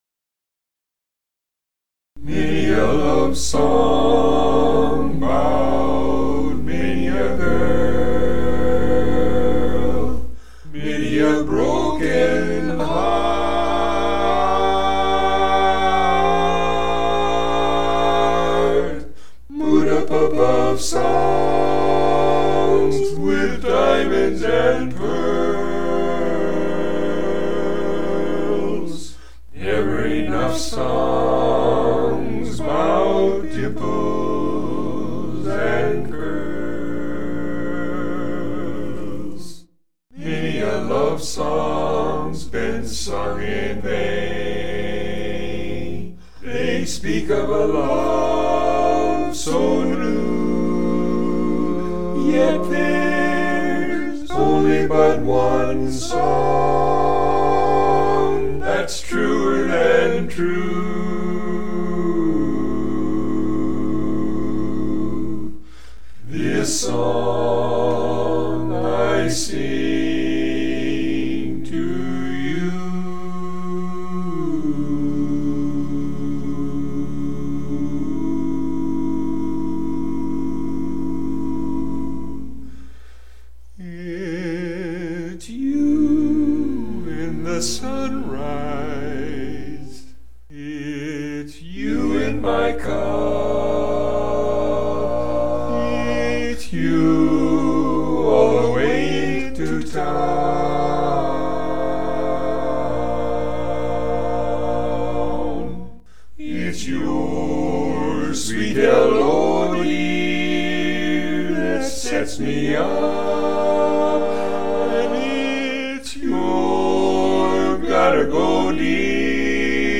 Barbershop Song